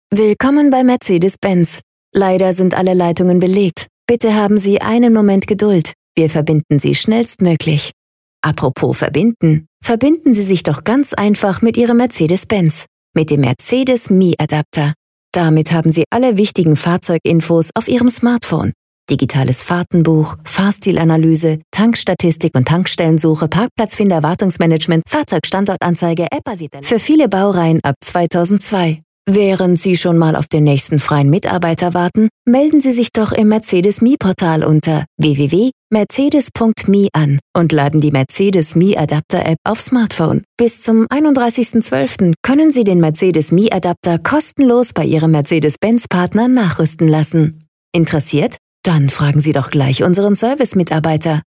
individuelle Telefonansagen und vieles mehr.
FEMALE GERMAN VOICE ARTIST and Voice Actress
My personal recording studio allows your audio file to be recorded very easily and guarantees the highest audio quality.